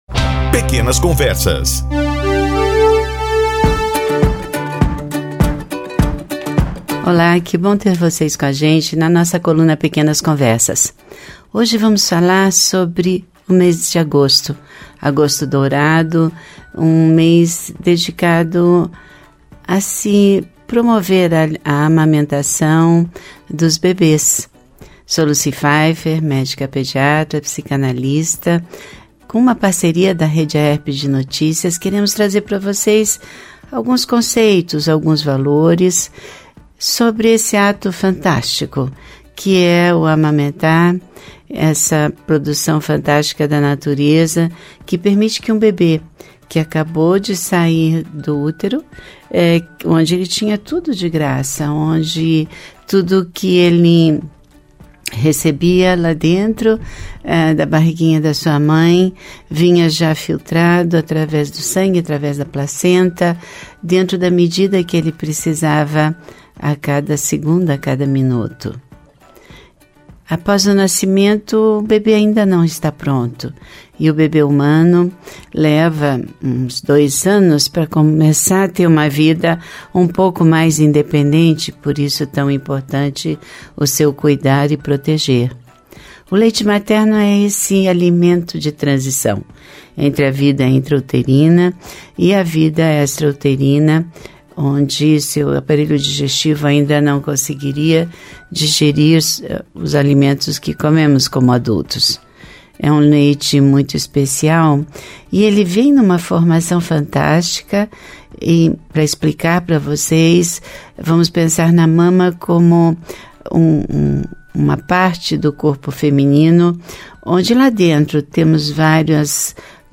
Uma conversa sobre amamentação.